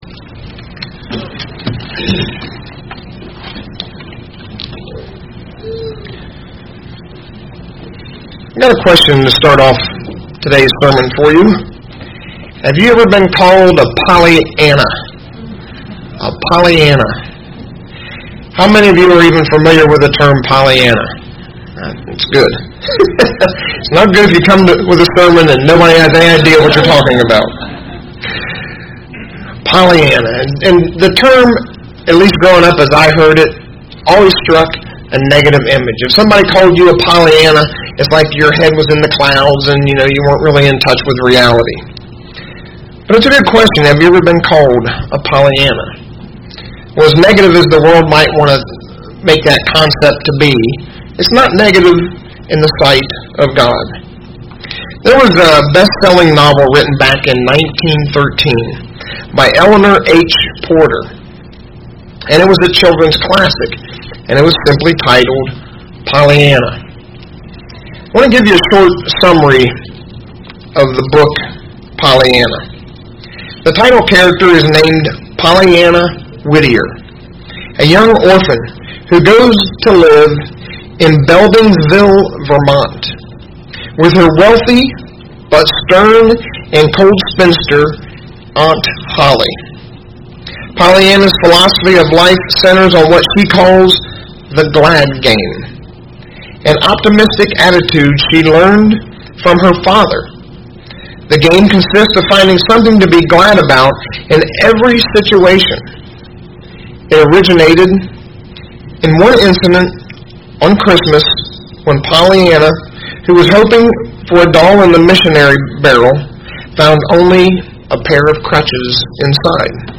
Given in Indianapolis, IN Ft. Wayne, IN
UCG Sermon Studying the bible?